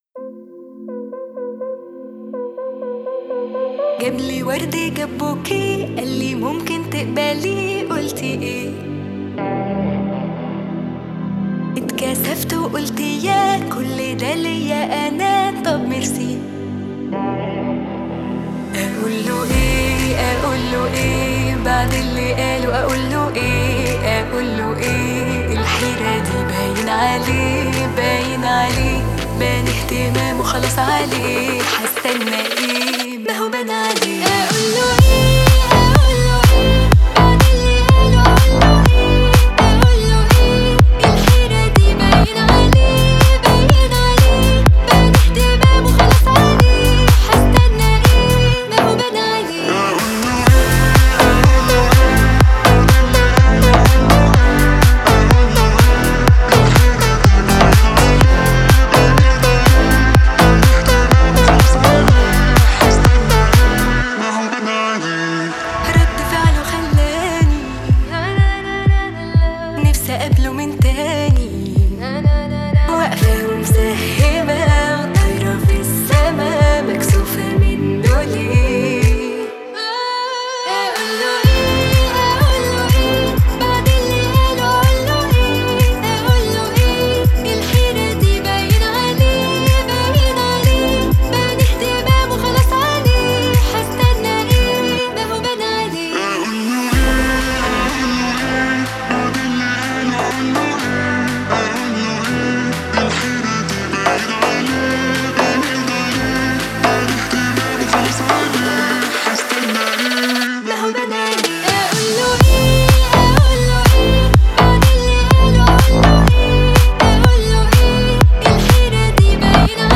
зажигательная электронная танцевальная композиция